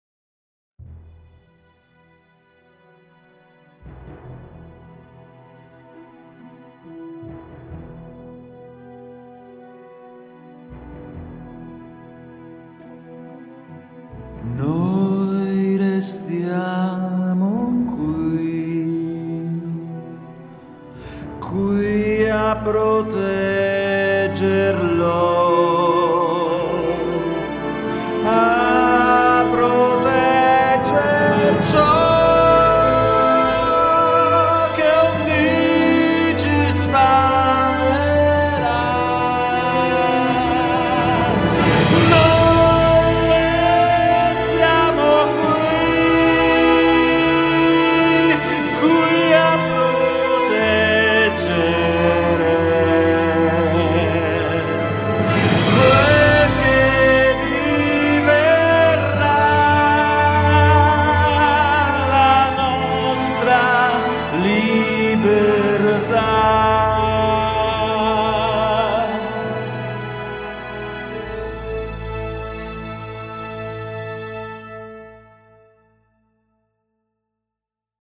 UOMINI
baritono.mp3